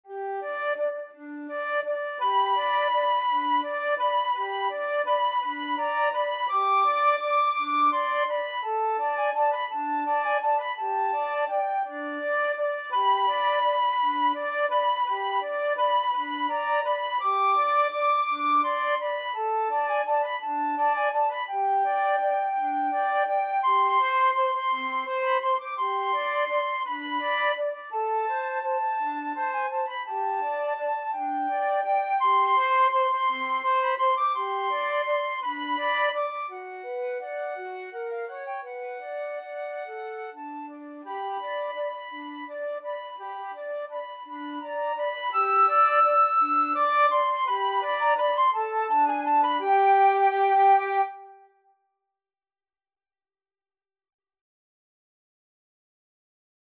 Free Sheet music for Flute Duet
Flute 1Flute 2
3/4 (View more 3/4 Music)
G major (Sounding Pitch) (View more G major Music for Flute Duet )
Tempo di valse =168
Classical (View more Classical Flute Duet Music)